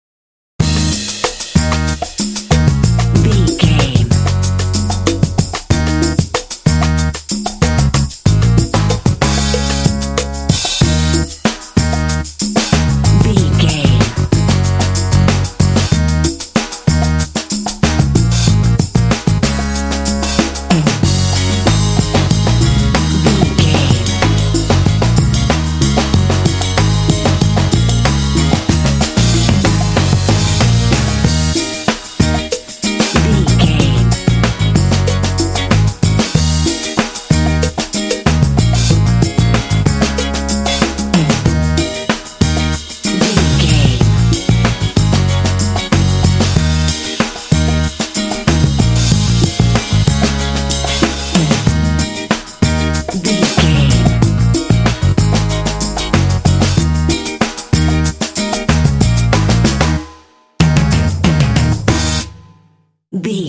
This cool and funky track is great for action games.
Aeolian/Minor
E♭
groovy
driving
saxophone
drums
bass guitar
electric guitar